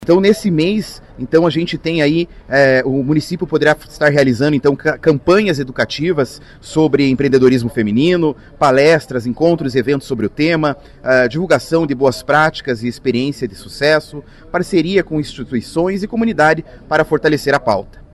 Ela está diretamente ligada ao Dia Internacional do Empreendedorismo Feminino, celebrado mundialmente em 19 de novembro por iniciativa da Organização das Nações Unidas (ONU). Explica o vereador Lórens Nogueira.